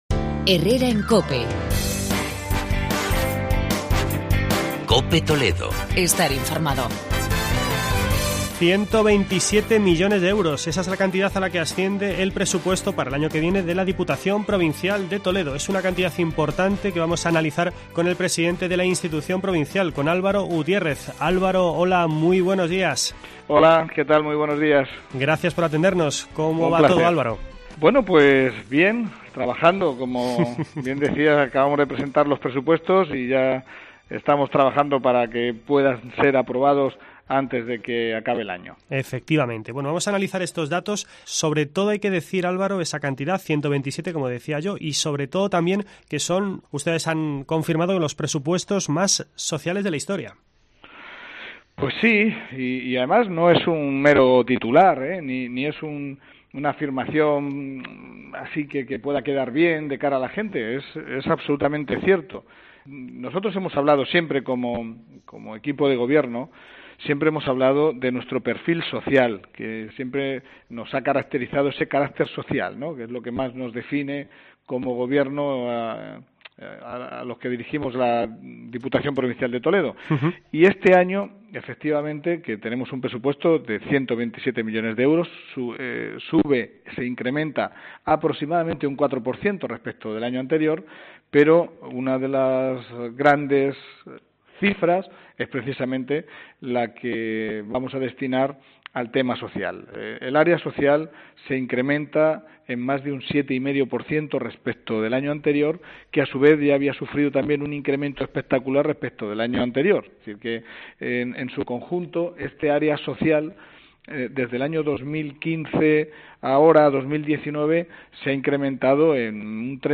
Entrevista con Álvaro Gutiérrez, presidente de la institución provincial.